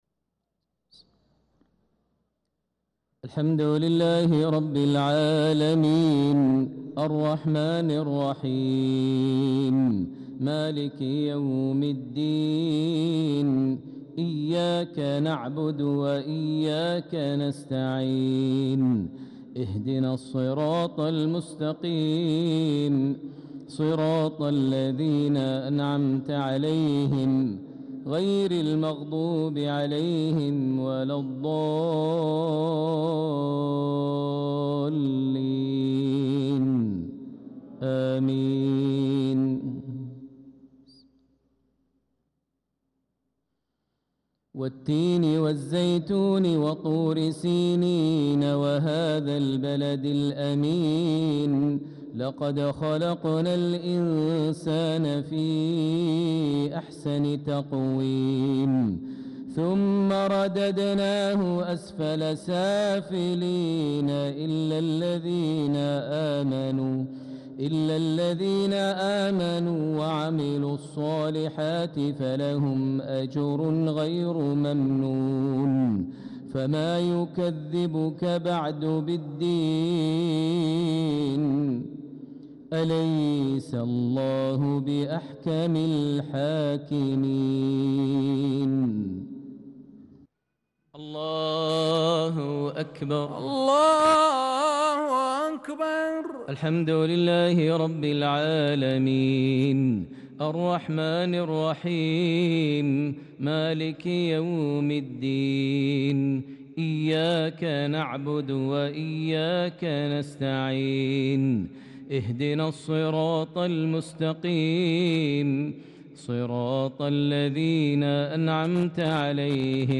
صلاة المغرب للقارئ ماهر المعيقلي 25 ذو الحجة 1445 هـ
تِلَاوَات الْحَرَمَيْن .